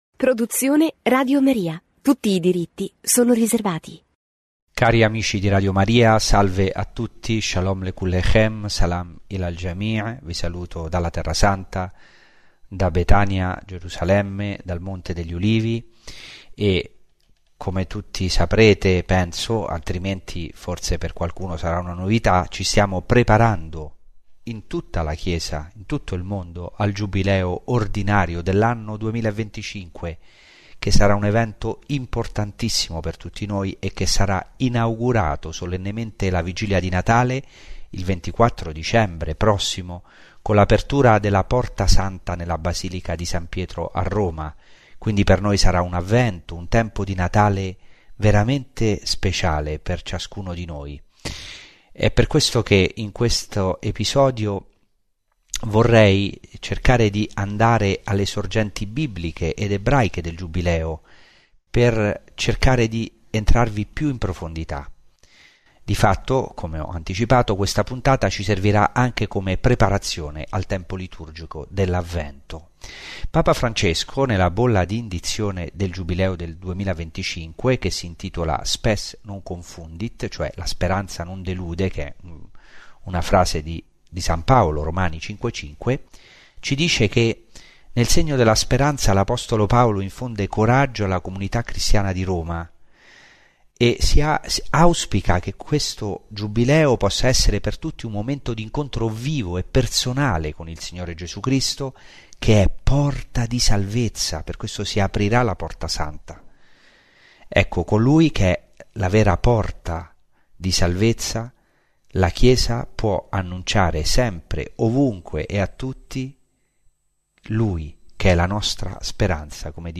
Ciclo di catechesi